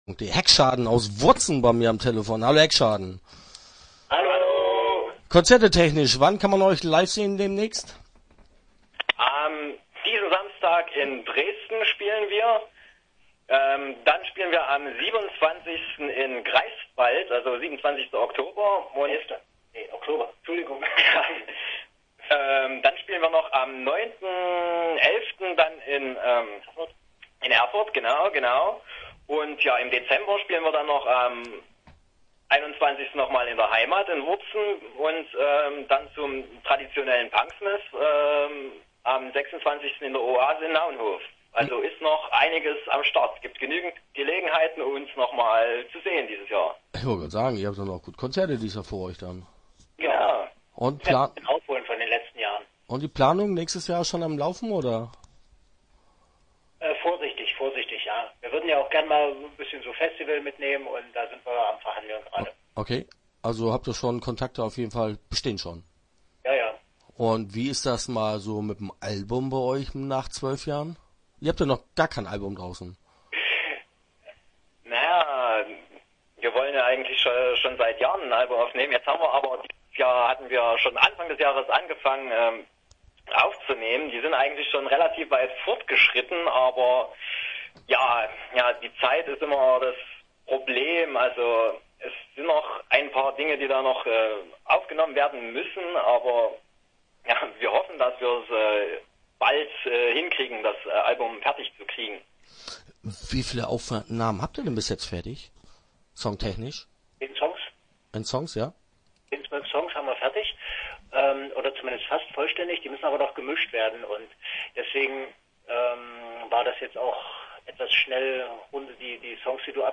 Start » Interviews » Heckschaden